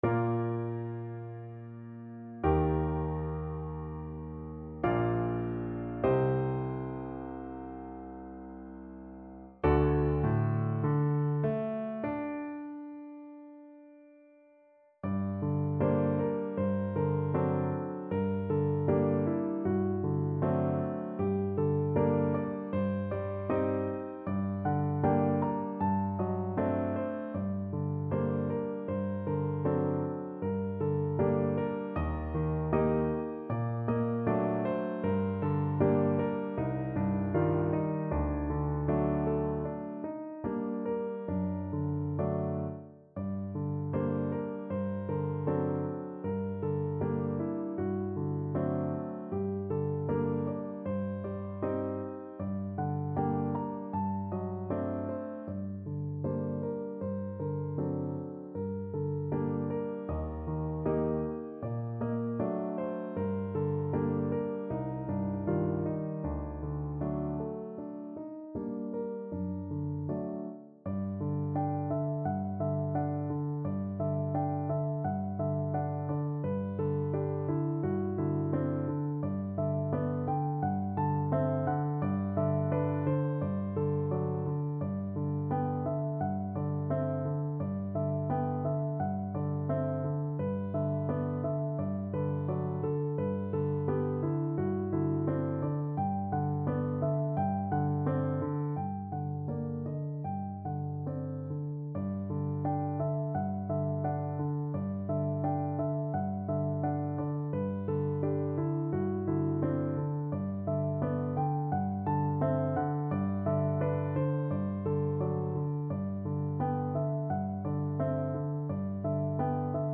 Classical Mussorgsky, Modest Une Larme (A Tear) Piano version
No parts available for this pieces as it is for solo piano.
Bb major (Sounding Pitch) (View more Bb major Music for Piano )
Largo
4/4 (View more 4/4 Music)
Classical (View more Classical Piano Music)
mussorgsky_une_larme_PNO.mp3